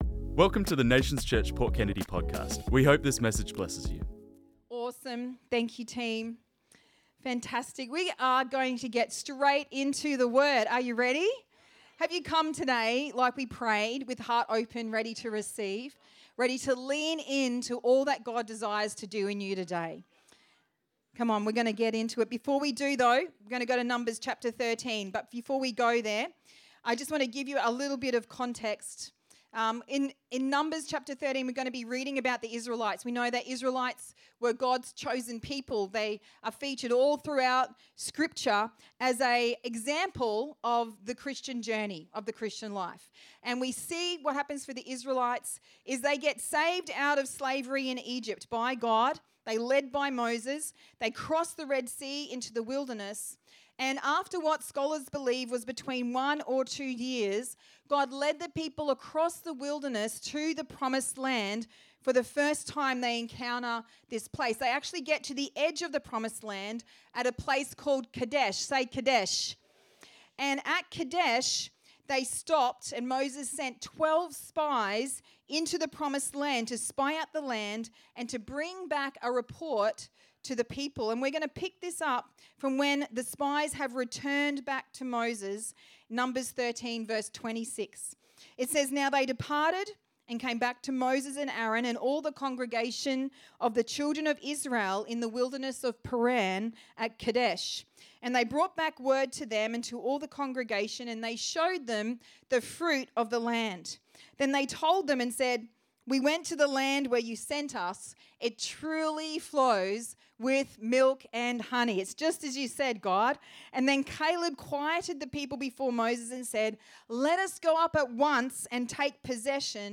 This message was preached on Sunday the 8th Feb 2026